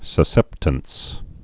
(sə-sĕptəns)